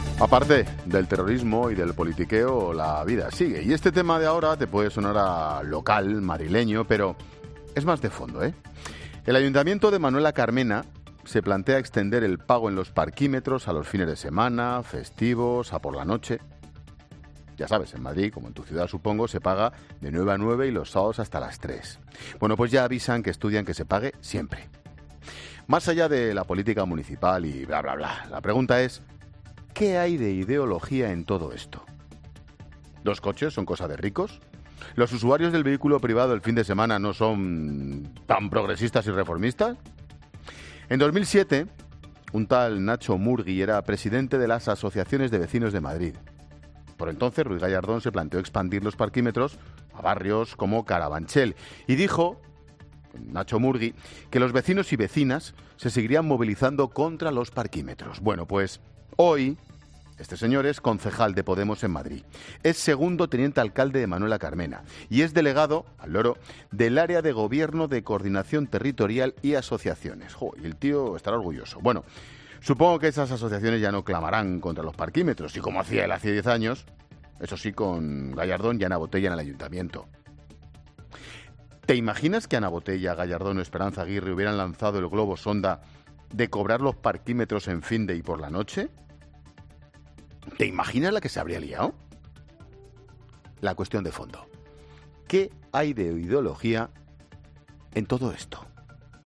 AUDIO: Monólogo 17h.